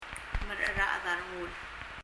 merecherached a rengul [mərə ? rə ? əð ə rəŋ(u)l] (???)